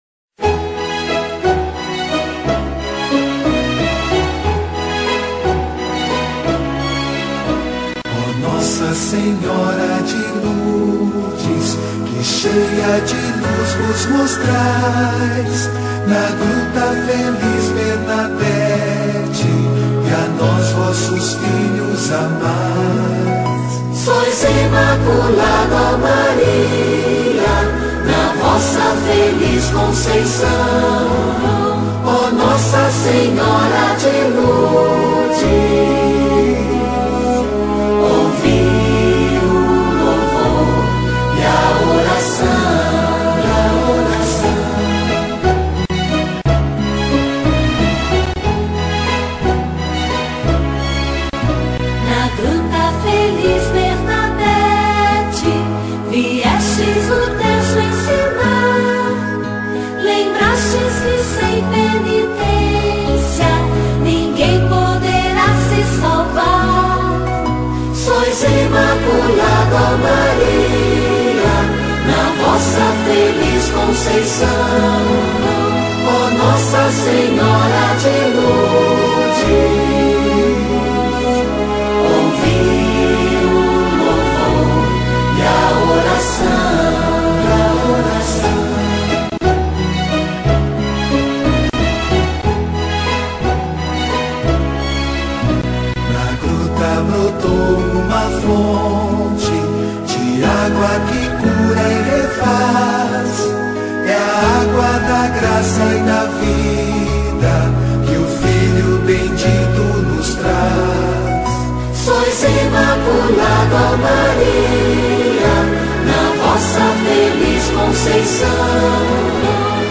01-Hino-Nossa-Senhora-de-Lourdes.mp3